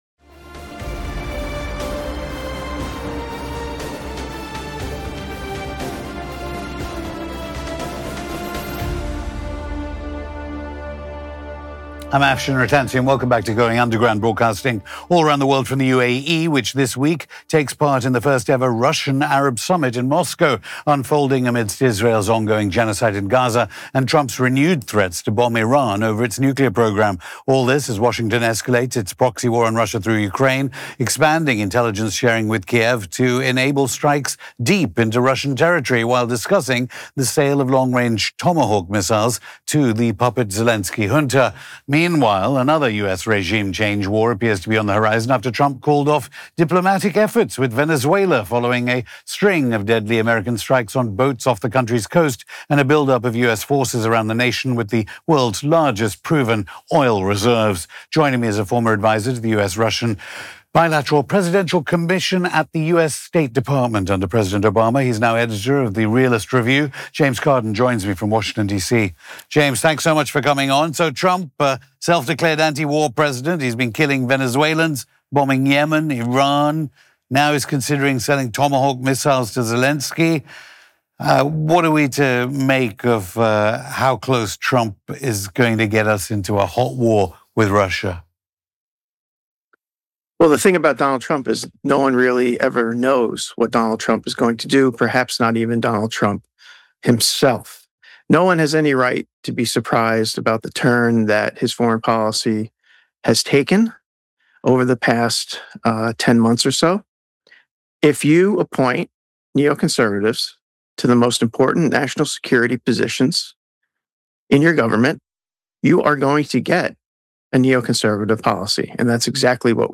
Going Underground Hosted by Afshin Rattansi Trump Has Surrounded Himself With Neocons AGAIN, War After War is Coming!